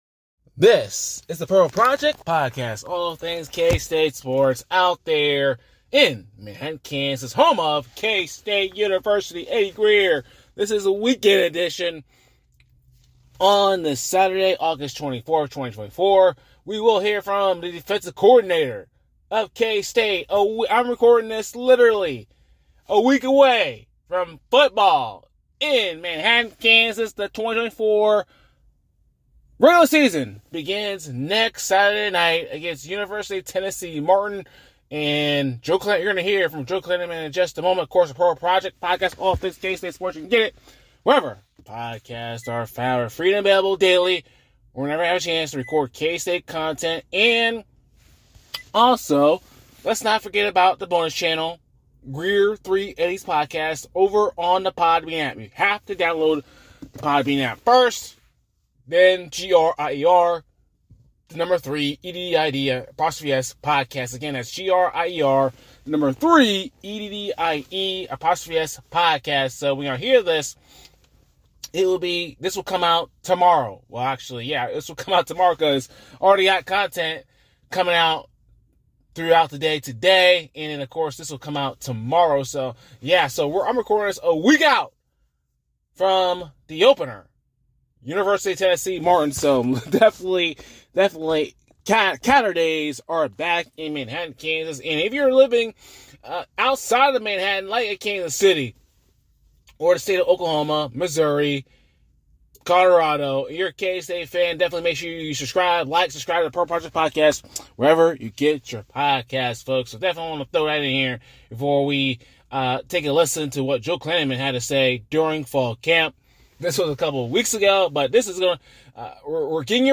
speaks to the media members ahead if the 2024 season!